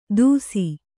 ♪ dūsi